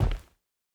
BootsLinoleum_05.wav